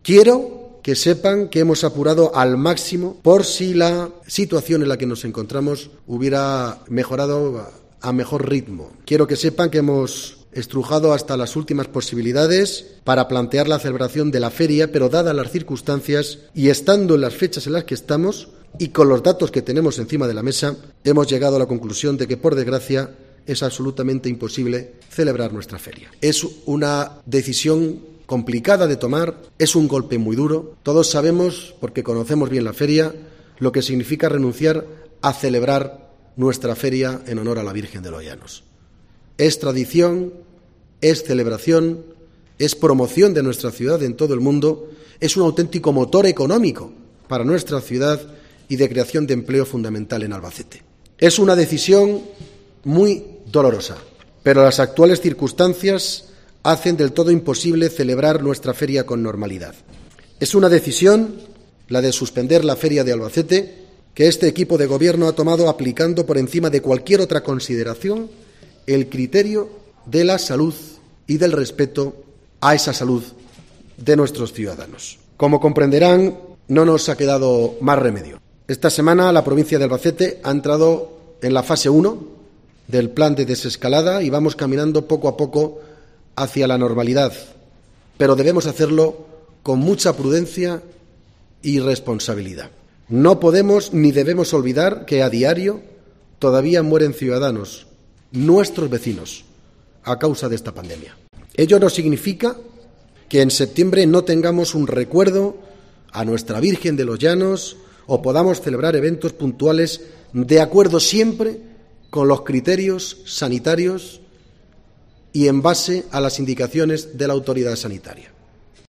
AUDIO | Estas han sido las frases más significativas de Vicente Casañ, alcalde de Albacete